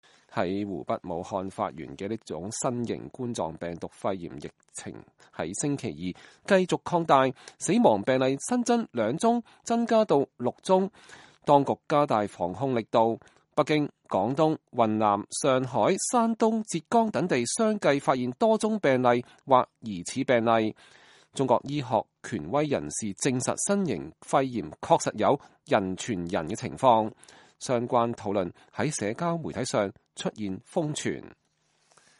美國之音記者在北京協和醫院現場觀察，戴口罩者明顯增多，呼吸道疾病內科診室門外走廊擠滿患者，當天無號可掛，週內預約已滿。
（根據採訪視頻整理，受訪者觀點不代表美國之音）